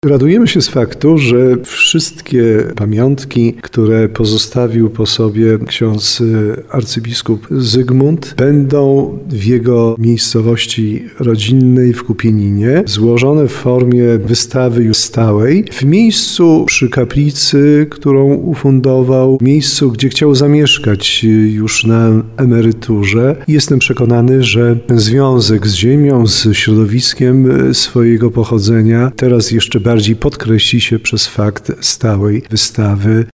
– Ta wystawa podkreśla związek śp. abpa Zygmunta Zimowskiego z ziemią jego pochodzenia – mówi biskup tarnowski Andrzej Jeż. Mowa o Kupieninie, gdzie otwarto stałą wystawę poświęconą abp. Zygmuntowi Zimowskiemu, który zmarł w 2016 roku.